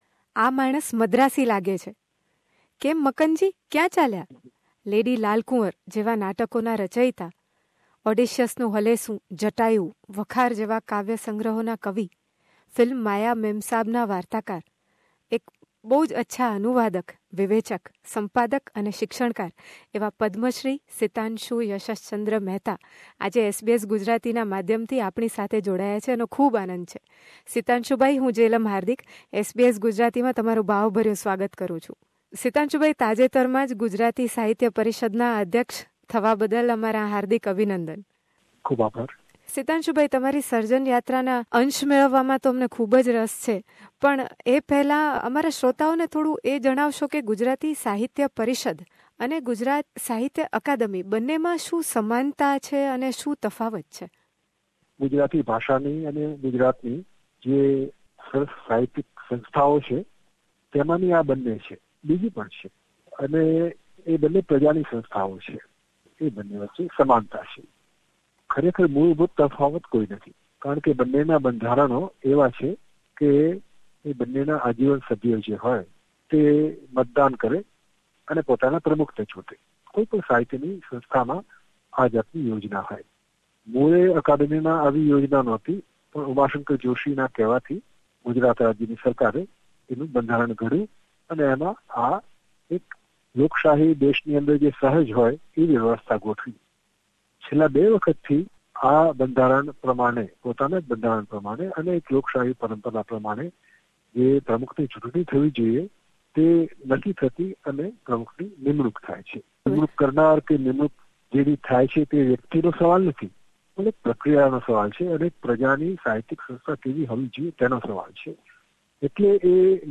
In conversation with Padmashree Sitanshu Yashaschandra, the President- elect of Gujarati Sahitya Parishad
World renowned poet, playright, translator, literary critic, academic and screenplay writer of film 'Maya Memsaab', Sitanshu Yashaschandra talks about the works of Sahitya Parishad, and explains how Gujaratis living outside India can become part of it. He recites his poem 'Samudra' to end this fascinating conversation.